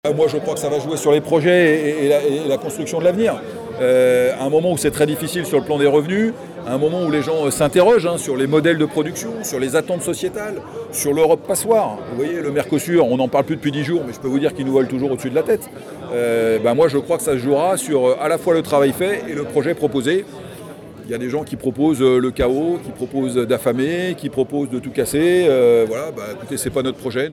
REACTION-DE-ARNAUD-ROUSSEAU.mp3